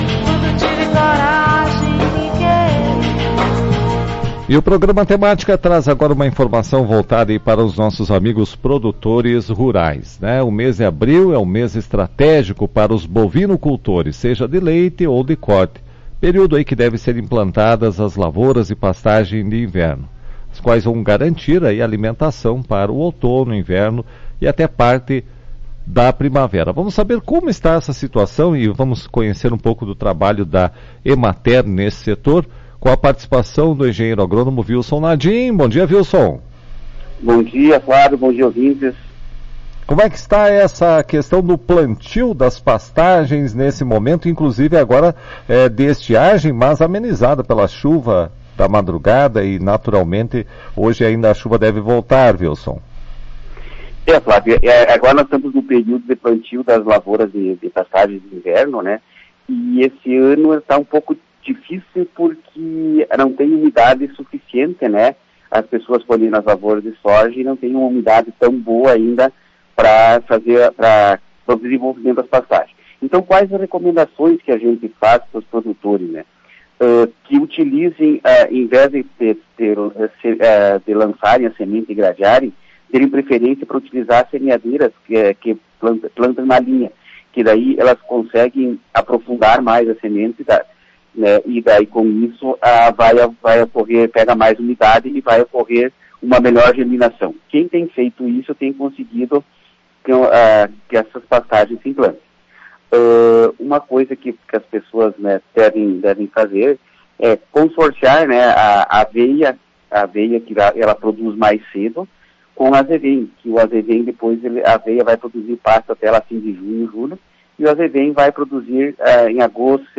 Em entrevista a Tua rádio Cacique